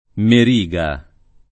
mar&go] o merigo [mer&go] s. m. (stor.); pl. -ghi — anche meriga [